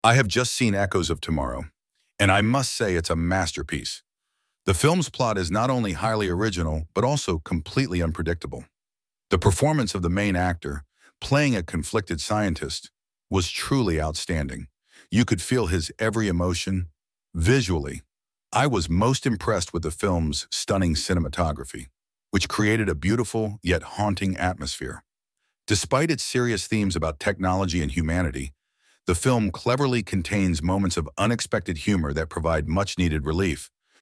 Part 2: You will hear a film critic reviewing a new movie.